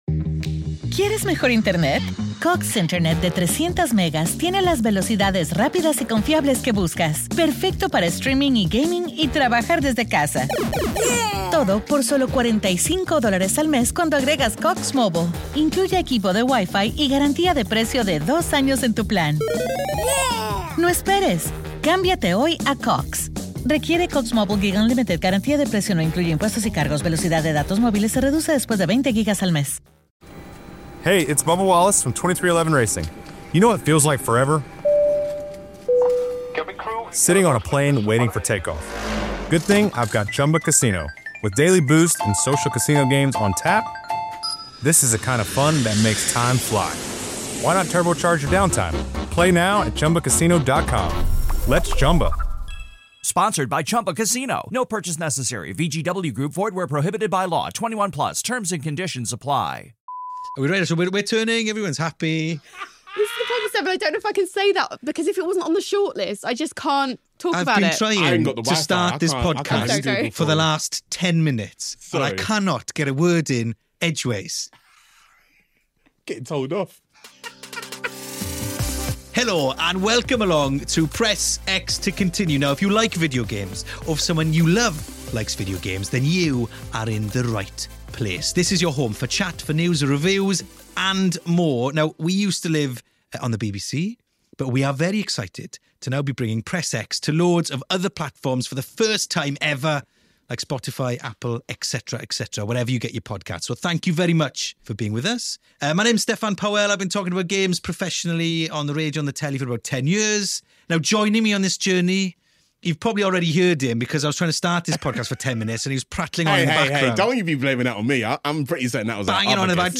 Gaming podcast Press X To Continue is back baby! In this episode the boys are joined by creator and presenter